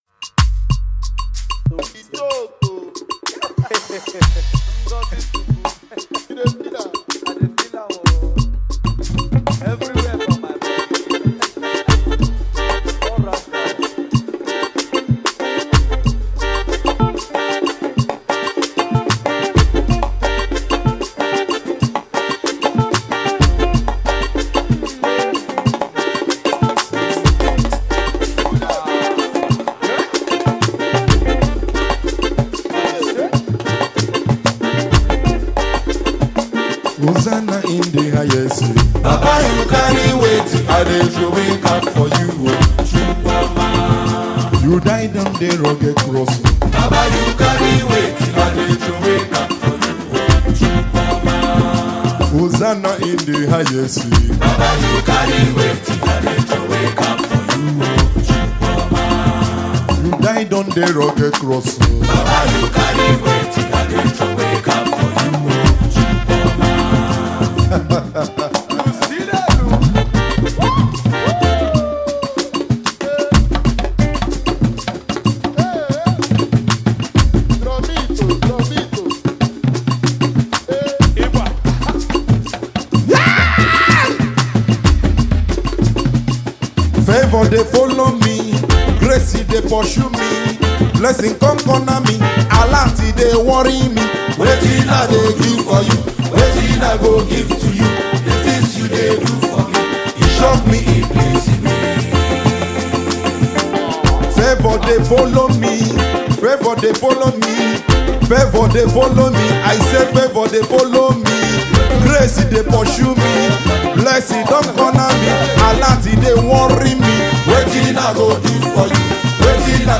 gospel music
songwriter and saxophonist.
traditional African rhythms